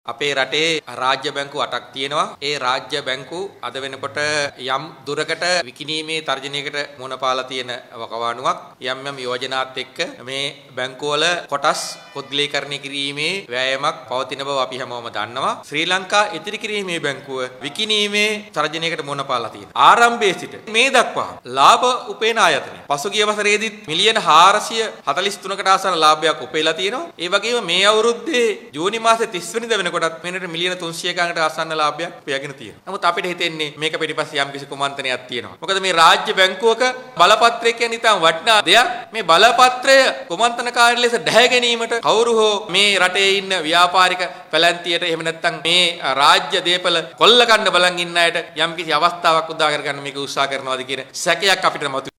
යටියන්තොට ප්‍රදේශයේ පැවති උත්සවයකින් අනතුරුව මාධ්‍ය වෙත අදහස් පළ කරමින් අමාත්‍ය රංජිත් සියඹලාපිටිය මහතා මේ බව පැවසුවා.